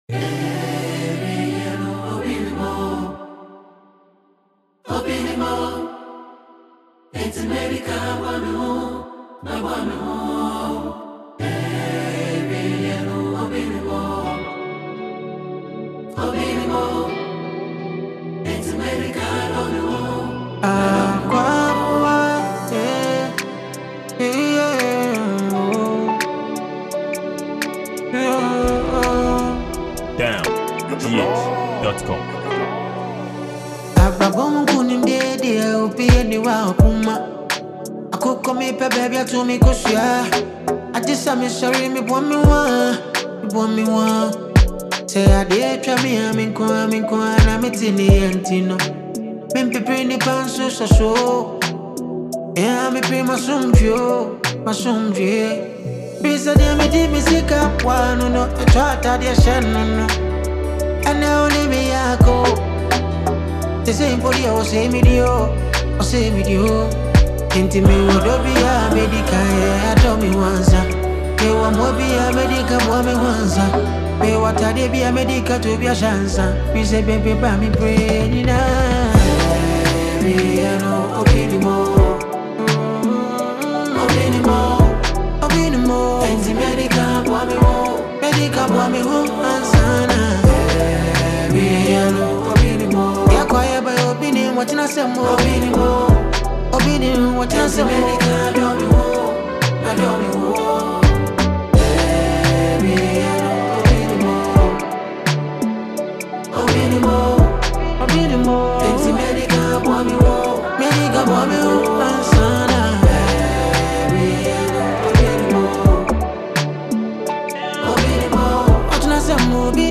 Ghanaian highlife singer and multi-talented songwriter.
Genre: Highlife